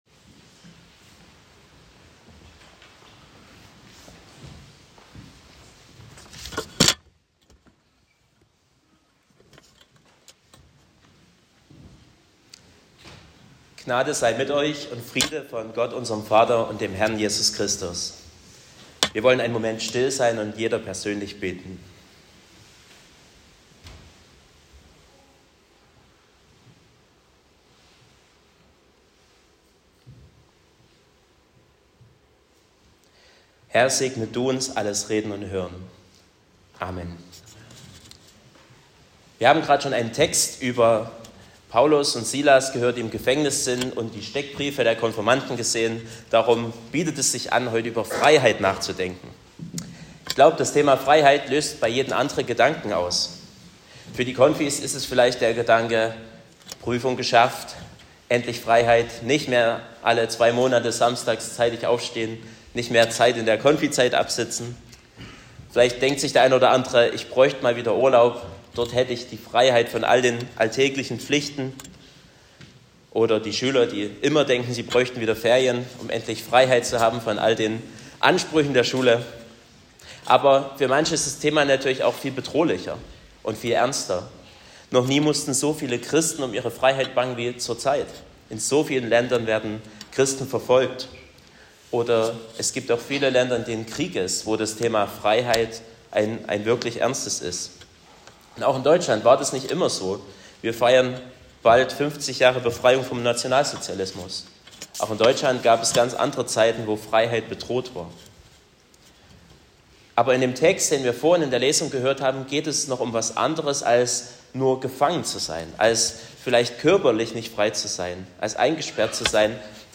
06.04.2025 – gemeinsamer Gottesdienst mit Konfivorstellung und Taufe
Predigt und Aufzeichnungen